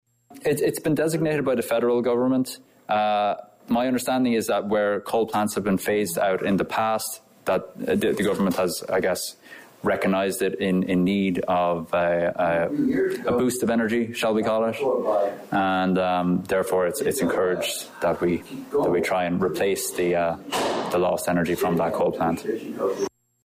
(Representatives from Solar Provider Group speak to citizens at Westville’s Public Library Thursday evening,  November 2, 2023.)